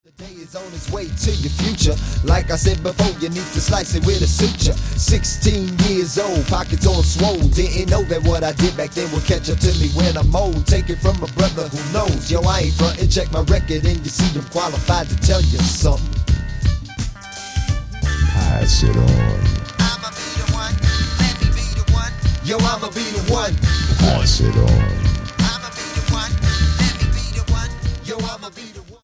gospel rapper
Style: Hip-Hop